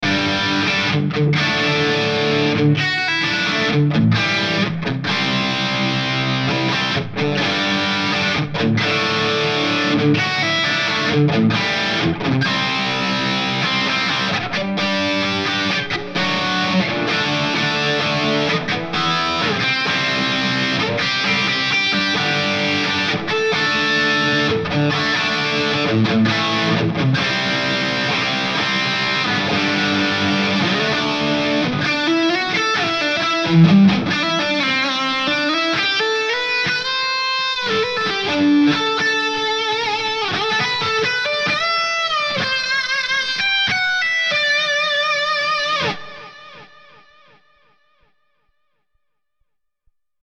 This Amp Clone rig pack is made from a Marshall JVM 410H Preamp.
IR USED: MARSHALL 1960A V30 SM57+ E906 POS 1, MARSHALL 1960A V30 SM57+ E906 POS 1
RAW AUDIO CLIPS ONLY, NO POST-PROCESSING EFFECTS
Hi-Gain